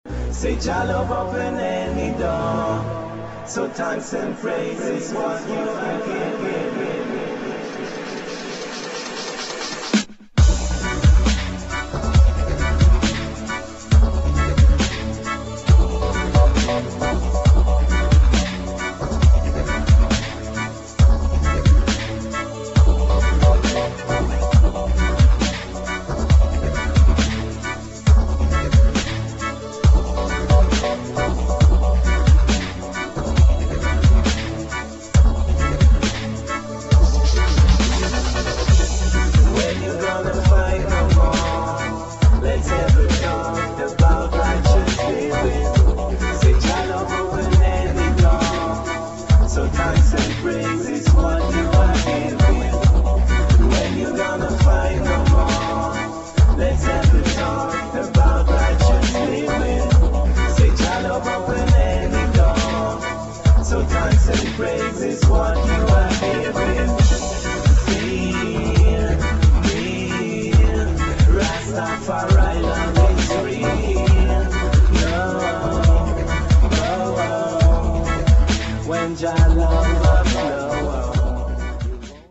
[ DUBSTEP ]